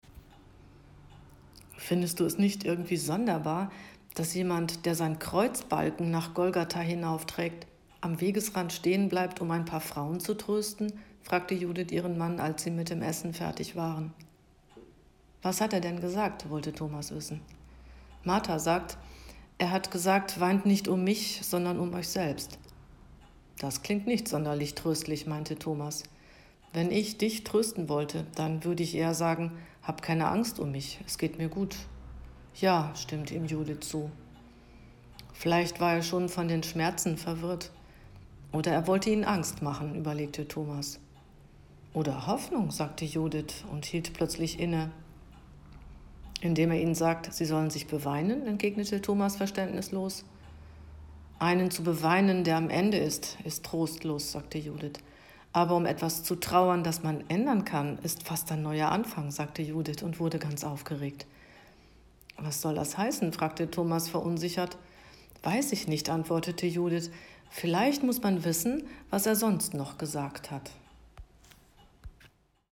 Dialog I